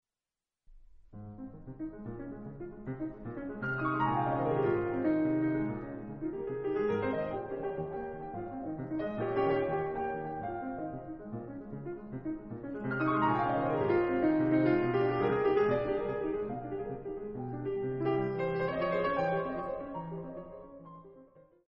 La troisième valse est une danse rapide (à un temps) dans la nuance PP d'un bout à l'autre. La main gauche, par un léger mouvement de noires staccato, soutient dans la première partie A un motif rythmique basé sur des accords répétés, puis dans B un motif mélodique qui module librement.
Dans ces valses la reprise de la première partie A n'apporte presque jamais de modification.